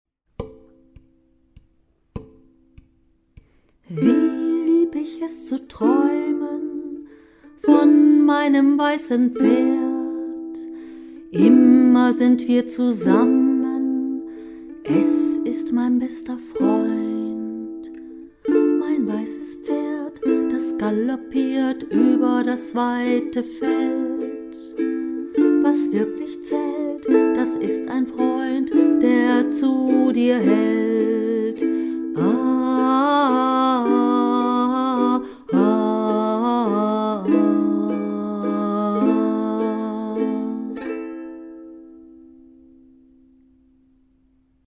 Normale Version zum Mitspielen: